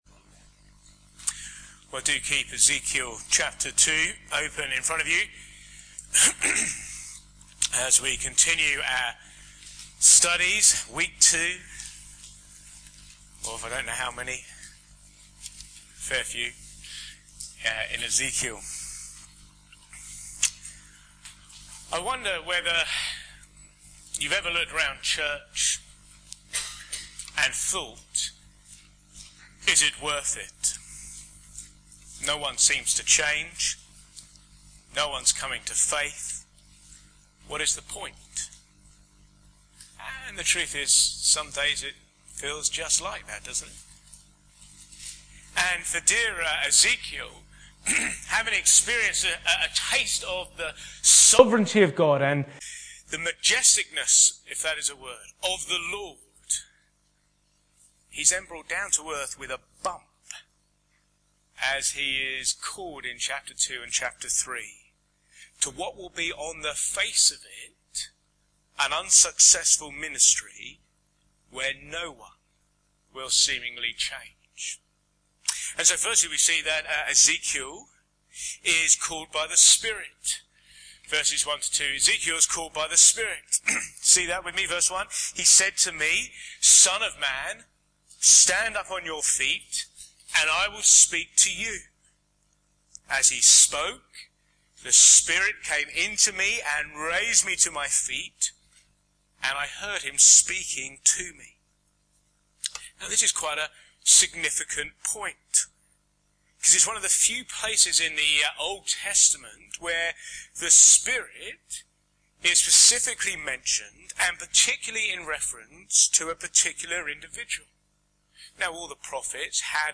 Please note that the sound quality is poor only for the first two minutes and then it reverts to ‘normal.’
Back to Sermons Speak my Words